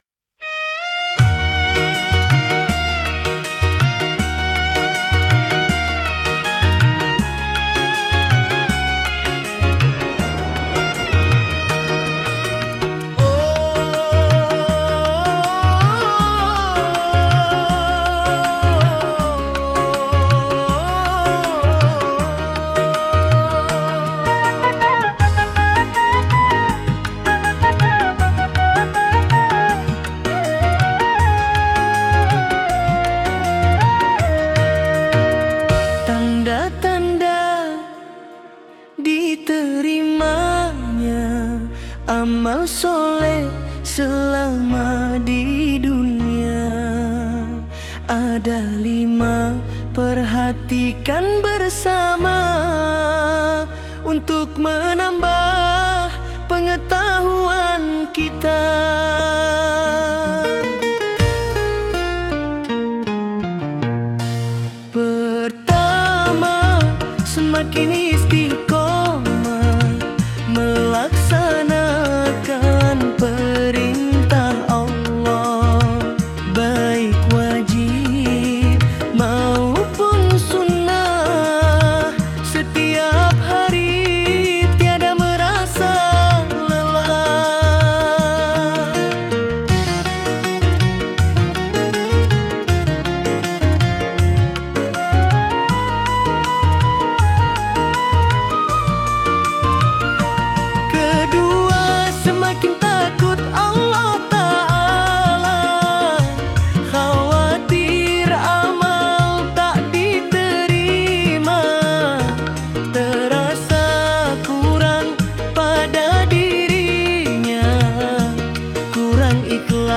Dengarkan musiknya yang syahdu dari Syair tersebut!